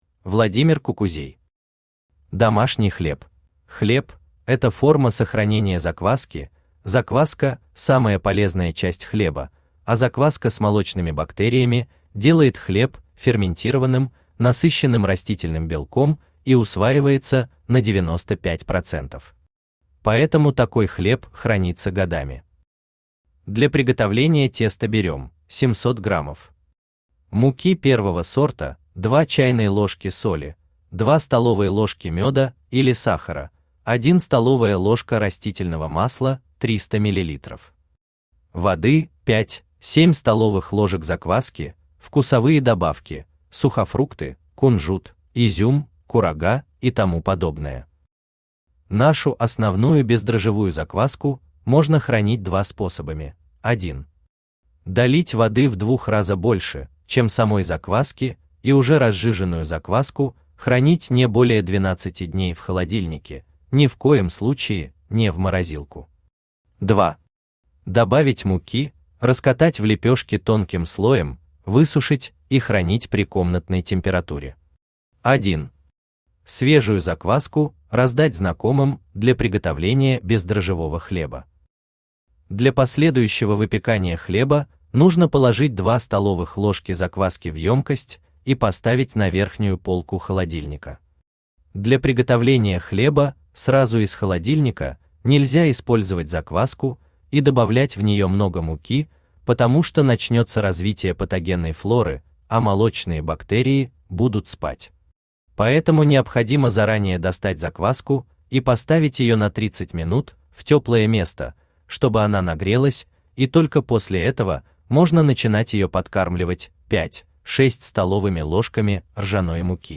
Аудиокнига Домашний хлеб | Библиотека аудиокниг
Aудиокнига Домашний хлеб Автор Владимир Николаевич Кукузей Читает аудиокнигу Авточтец ЛитРес.